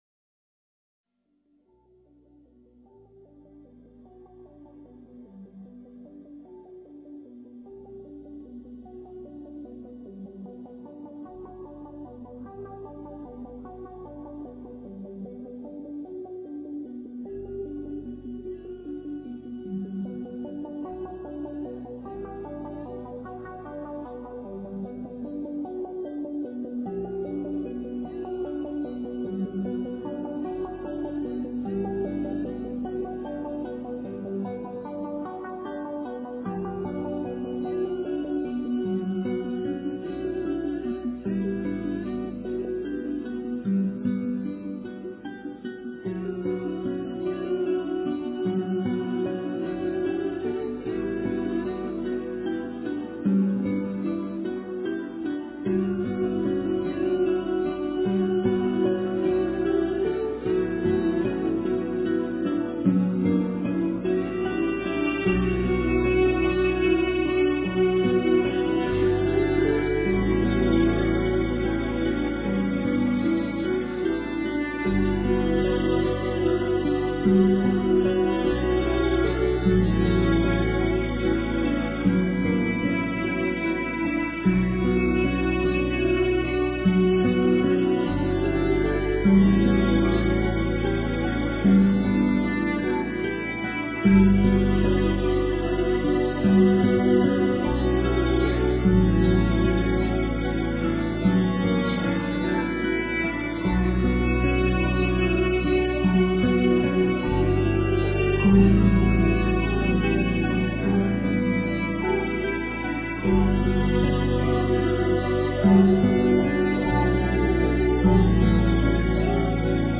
无尽的思量Endless Thoughts--瑜伽静心曲
无尽的思量Endless Thoughts--瑜伽静心曲 冥想 无尽的思量Endless Thoughts--瑜伽静心曲 点我： 标签: 佛音 冥想 佛教音乐 返回列表 上一篇： 透过你温柔的气息Through your Tender Breath--瑜伽静心曲 下一篇： 禅悦--佛光山梵呗 相关文章 七里莲海宝塔寺 - 伴奏--印良法师 七里莲海宝塔寺 - 伴奏--印良法师...